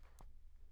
＊カラオケはリピートしています
カラオケ音源10inB